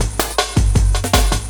06 LOOP06 -L.wav